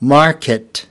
market /10/ /’mɑːk.ɪt/ /’mɑːk.ɪt/
market-5.mp3